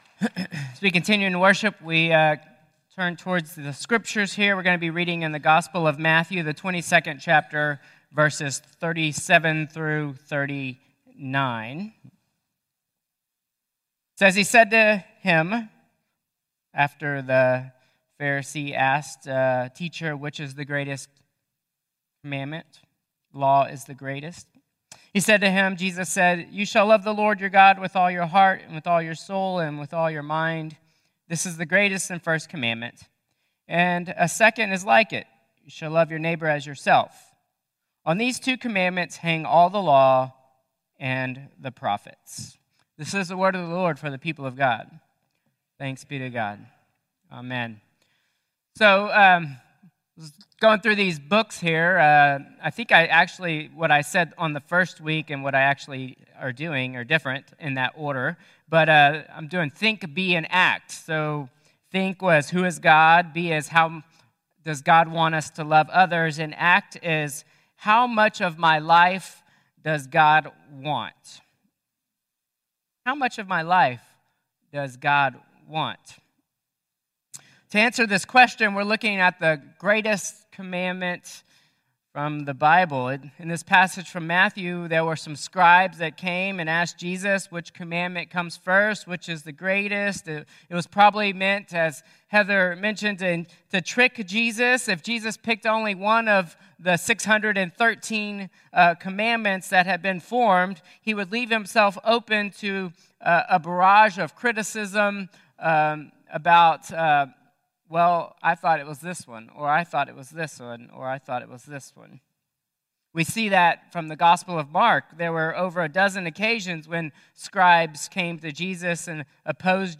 Contemporary Service 5/18/2025